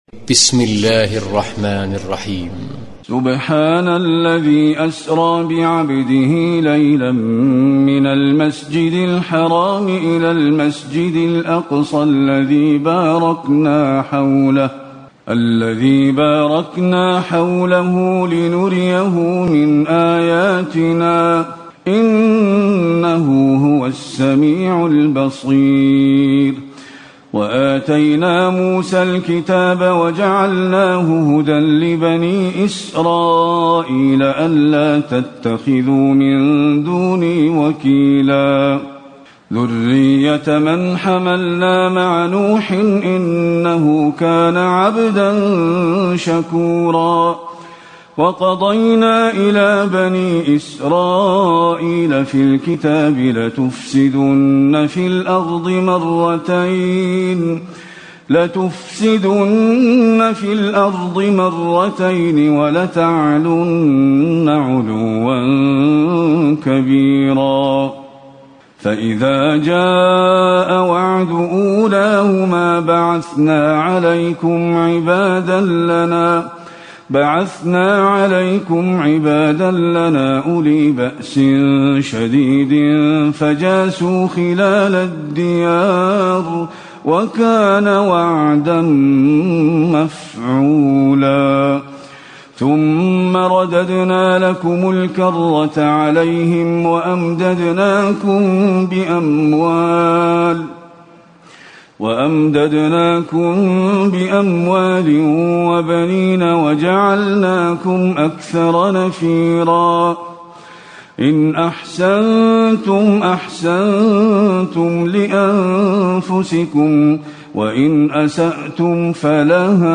تراويح الليلة الرابعة عشر رمضان 1437هـ من سورة الإسراء (1-100) Taraweeh 14 st night Ramadan 1437H from Surah Al-Israa > تراويح الحرم النبوي عام 1437 🕌 > التراويح - تلاوات الحرمين